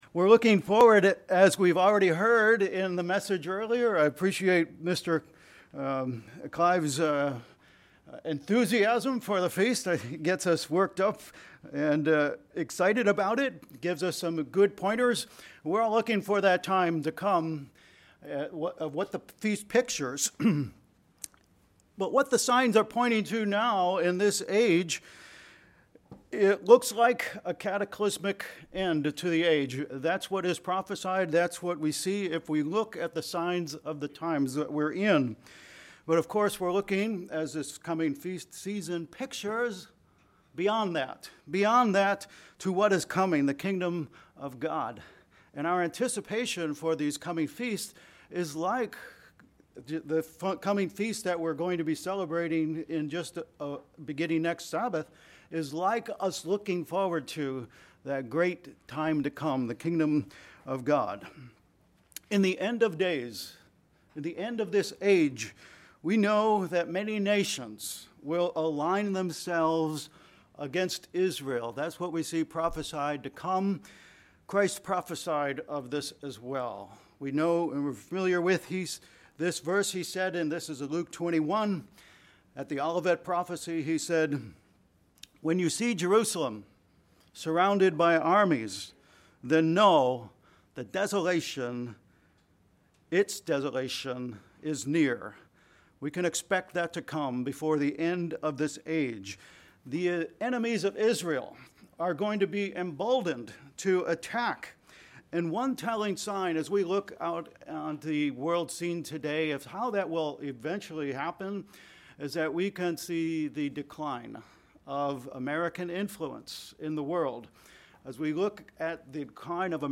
Given in Vero Beach, FL Ft. Myers, FL